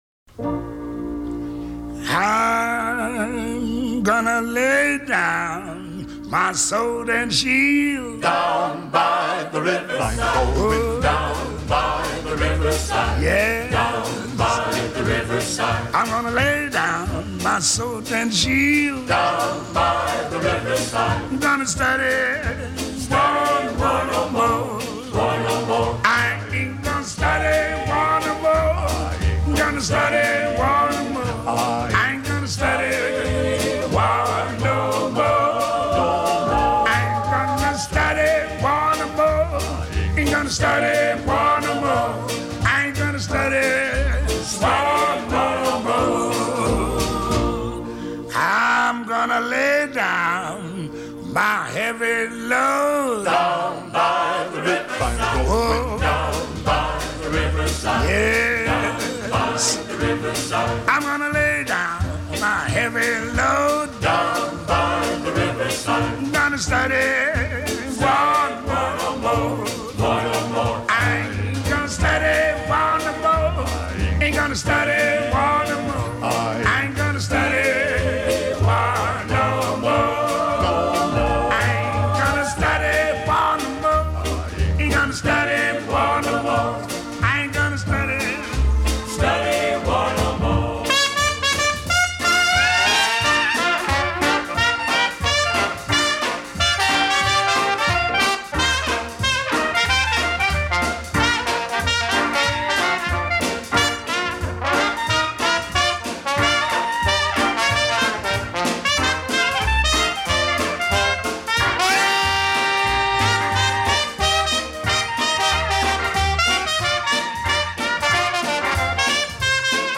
Лично я обожаю джаз, особливо традиционный!